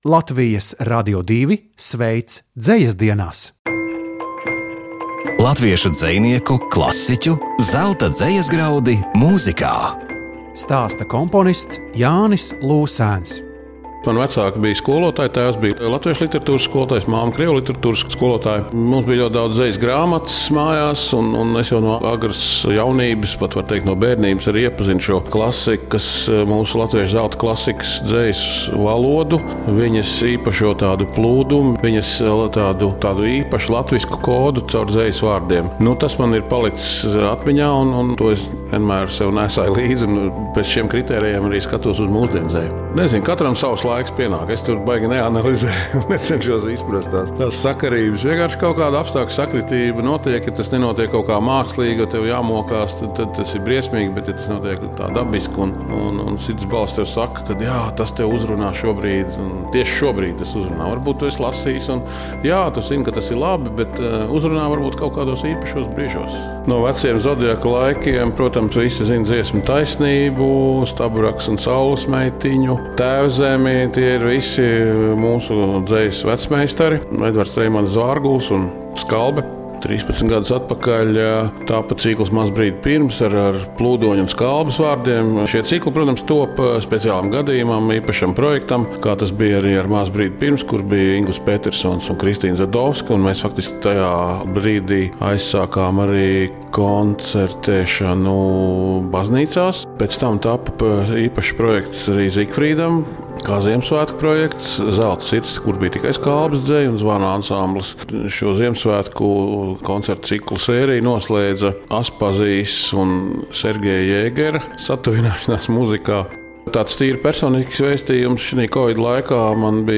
Latviešu dzejnieku - klasiķu zelta graudi mūzikā. Stāsta komponists Jānis Lūsēns.